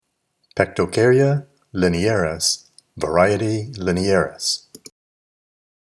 Pronunciation/Pronunciación:
Pec-to-cár-y-a li-ne-à-ris var. li-ne-à-ris